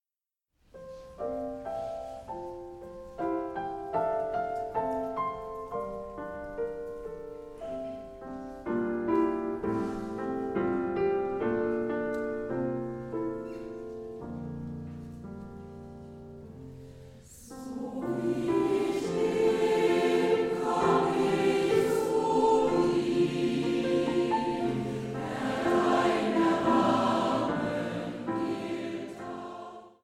• kurzweilige Zusammenstellung verschiedener Live-Aufnahmen
Chor, Klavier